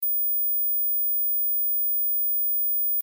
15khz- 39yrs old and younger